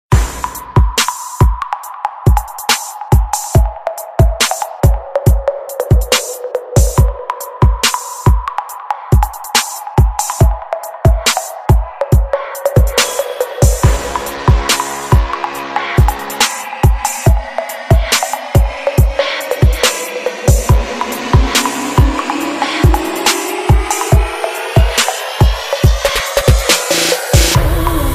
Dance
best , electro , house ,